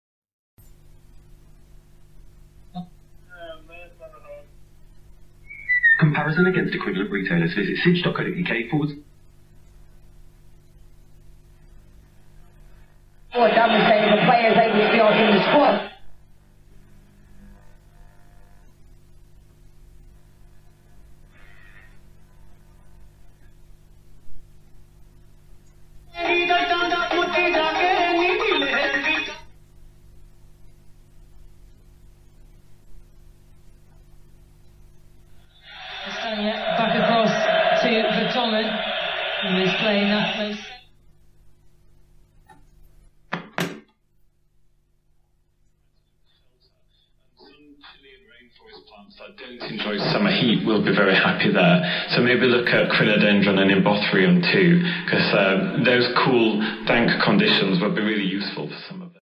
‘Tuning in’ with my 1957 Ferguson U354 radio